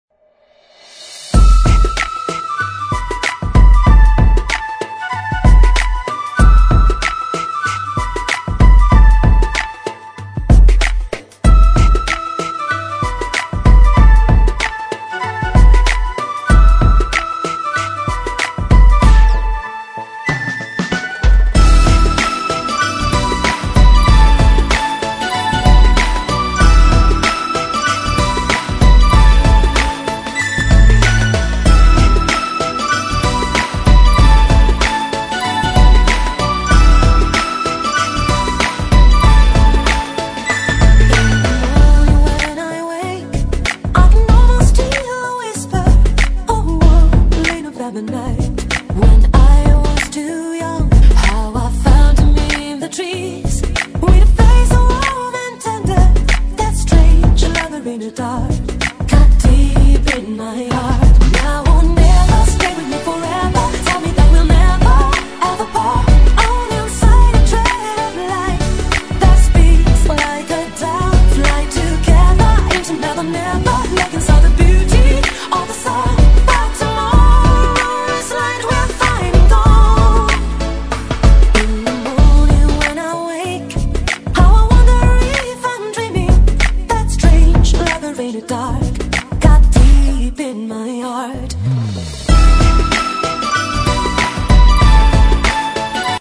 Oriental Radio Edit
※試聴は音質を落しています。